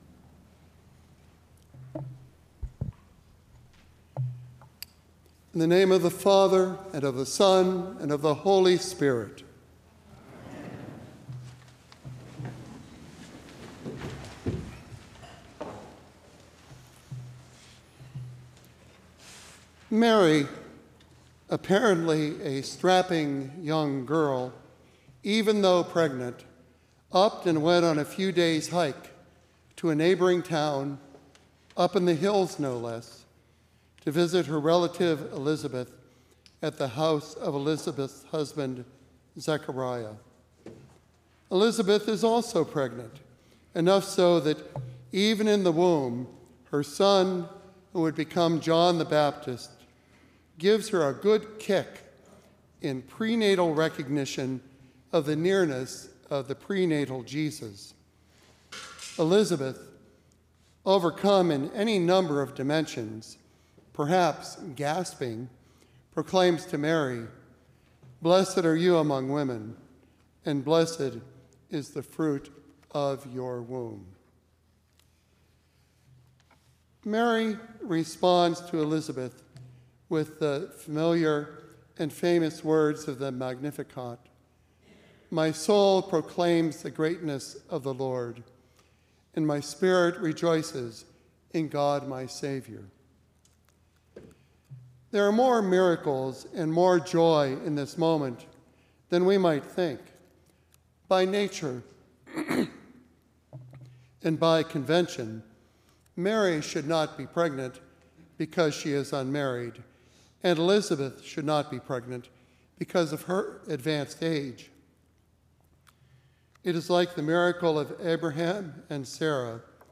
Trinity Episcopal Sermons, Concord, MA